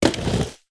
SD_SFX_RollerSkate_SoftLand.wav